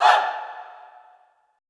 Index of /90_sSampleCDs/Best Service - Extended Classical Choir/Partition I/FEM SHOUTS
FEM HAH   -R.wav